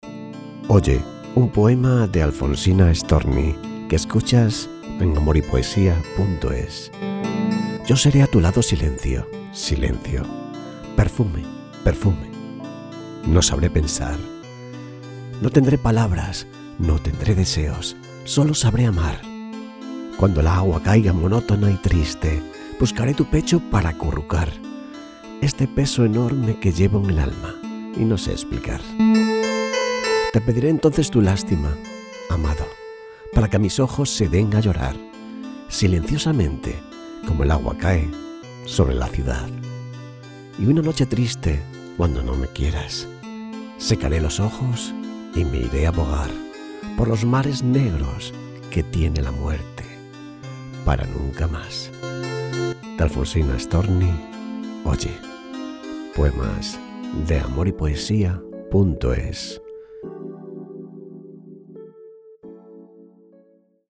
Poemas de amor recitados.